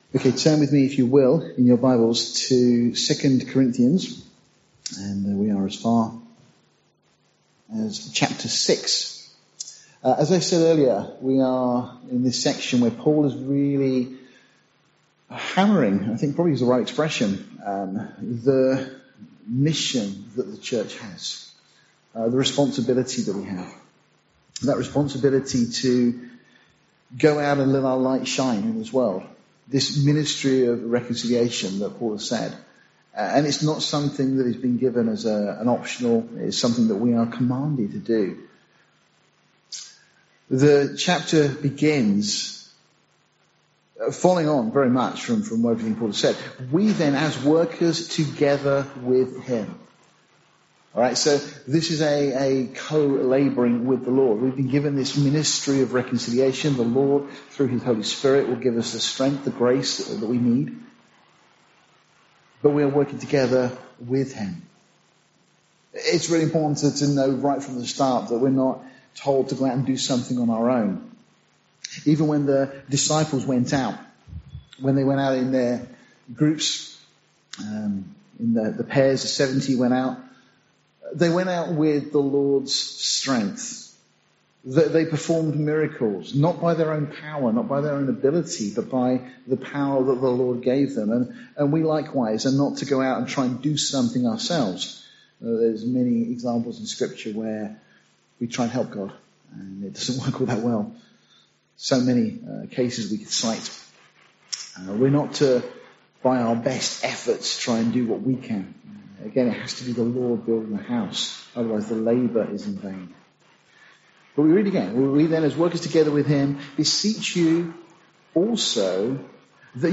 Series: Sunday morning studies Tagged with verse by verse